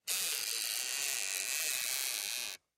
Звуки регулятора громкости
Звук вращаем переменный резистор